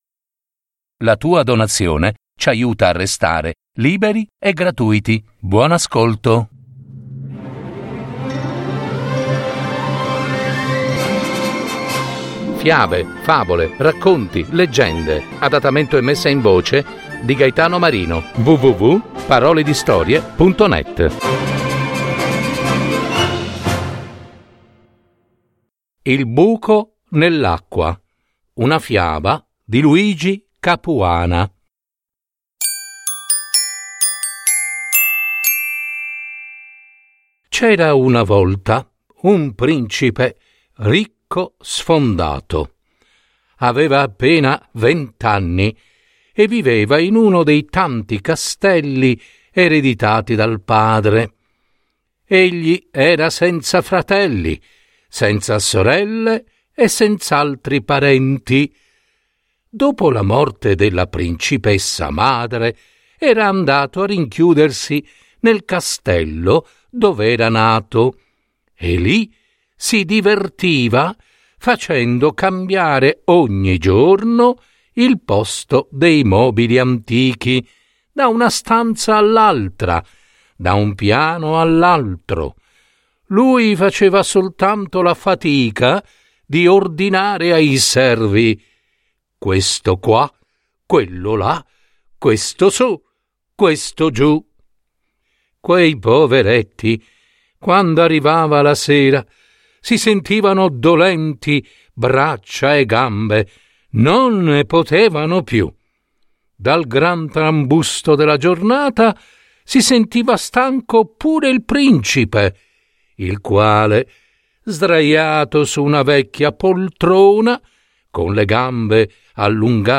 Adattamento e messa in voce
Molto bella la fiaba e molto ben recitata